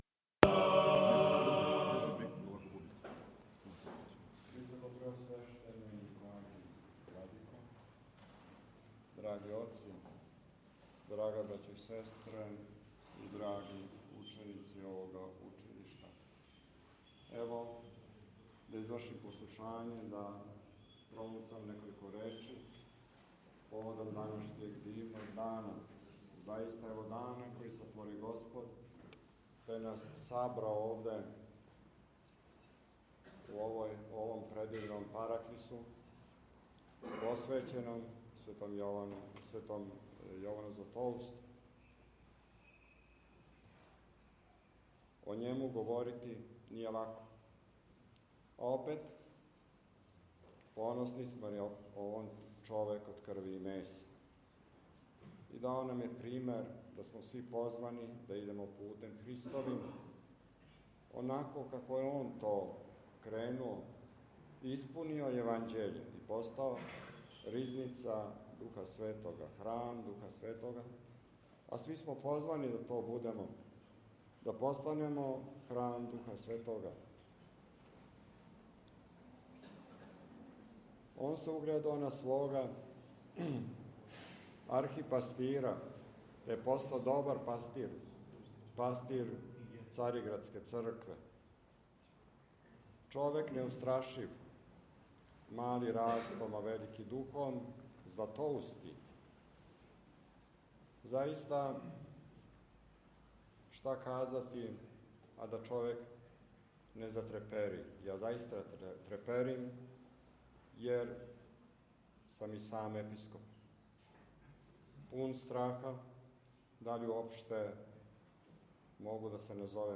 Након прочитаног Јеванђеља вернима се пригодним речима обратио Његово Преосвештенство Епископ хвостански Господин Алексеј рекавши:
Беседа Његовог Преосвештенства Епископа хвостанског г. Алексеја